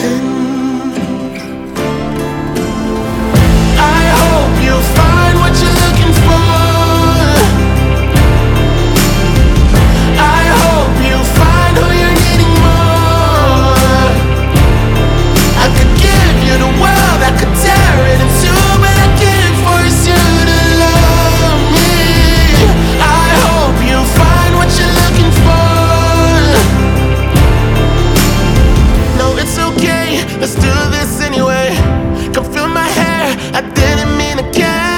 2025-06-06 Жанр: Поп музыка Длительность